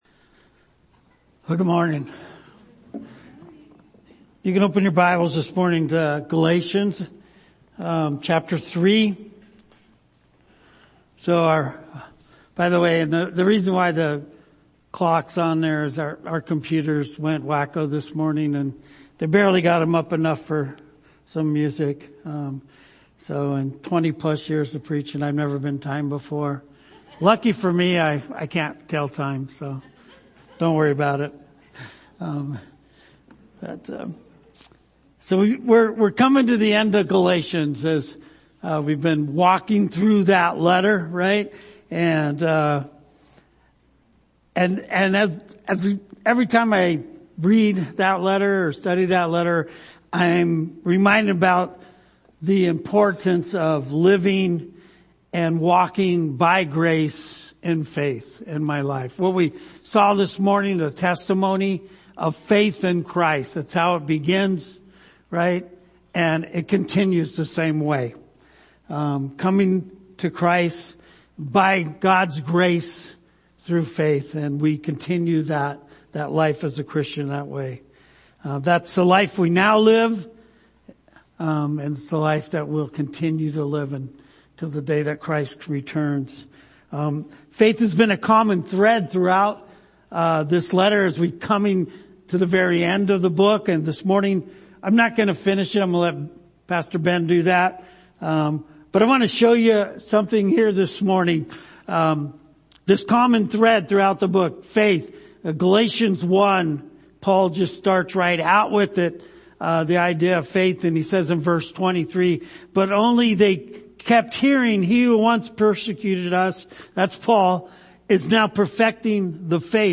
Galatians Service Type: Sunday Morning « Today and That Day